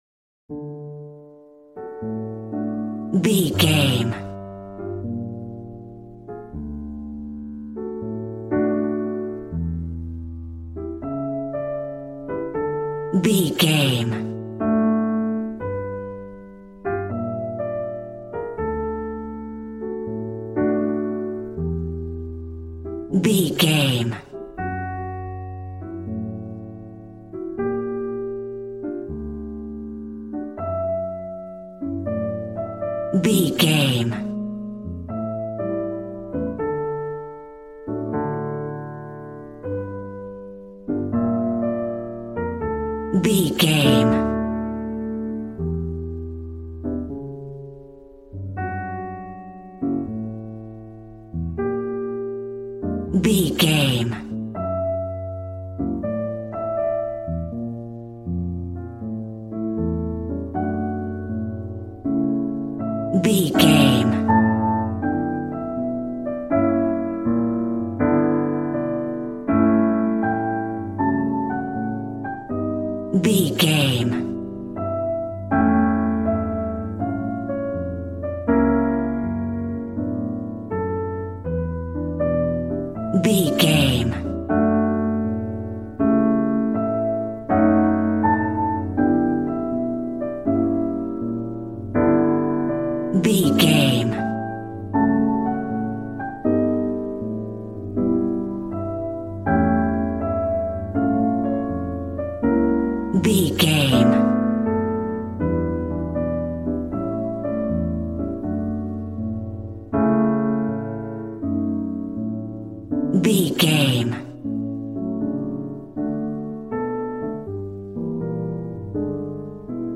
Smooth jazz piano mixed with jazz bass and cool jazz drums.,
Ionian/Major
piano